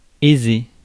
Ääntäminen
IPA : /ˈprɒsp(ə)rəs/